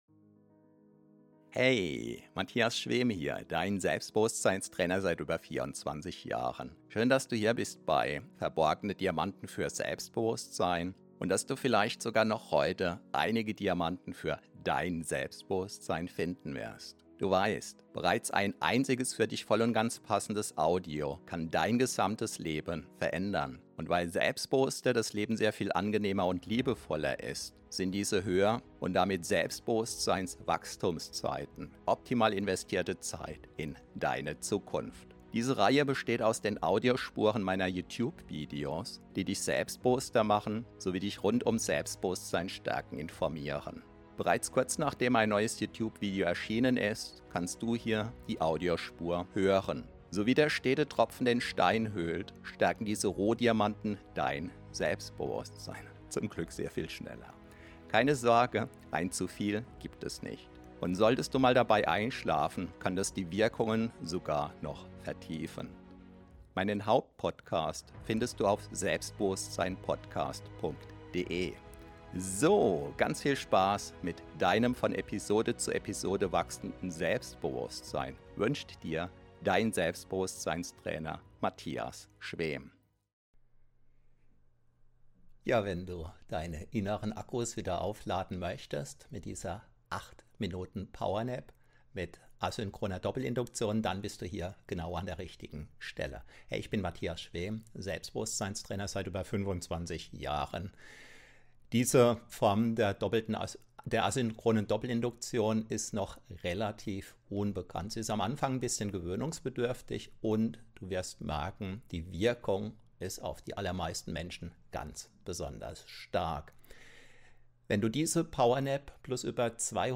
ACHTUNG: Extra stark! Anfangs etwas gewöhnungsbedürftig hat diese Power Nap Hypnose eine extrem starke Wirkung. Nach 8 Minuten wirst du wieder in die Gegenwart zurückgeholt.